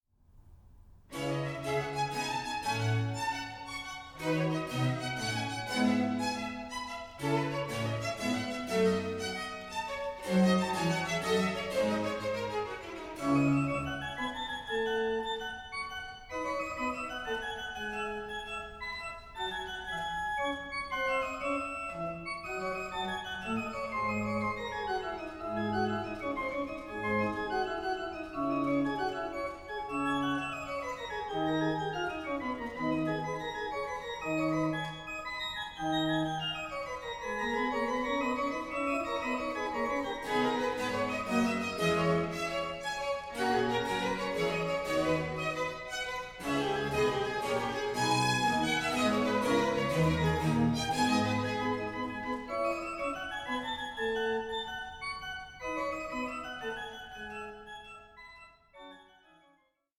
Organ Concerto No.16 in F major